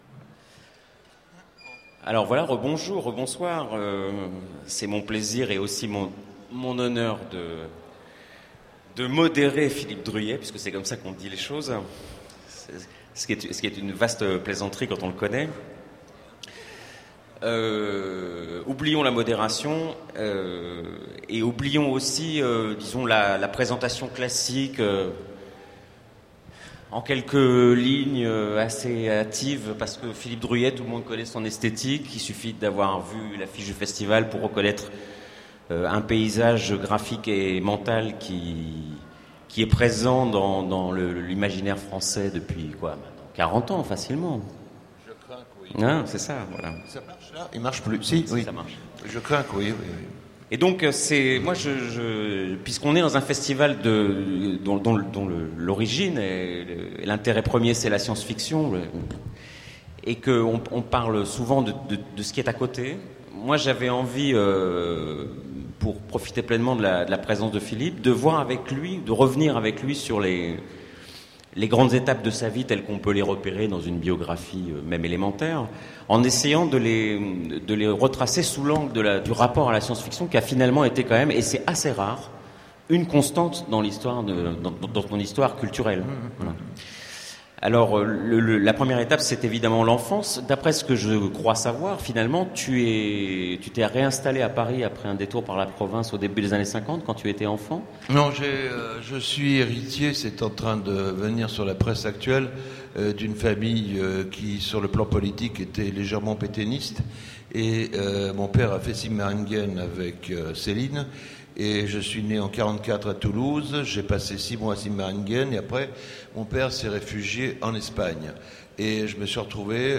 Voici l'enregistrement de la rencontre avec Philippe Druillet aux Utopiales 2010. Grand nom de la science-fiction, Philippe Druillet est un artiste complet.